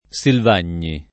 [ S ilv # n’n’i ]